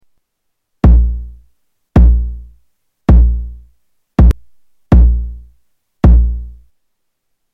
AirBase 99 bassdrum 3
Category: Sound FX   Right: Personal